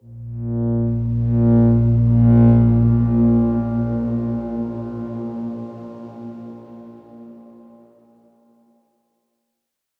X_Darkswarm-A#1-pp.wav